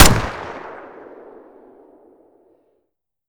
Pistol_Shoot.wav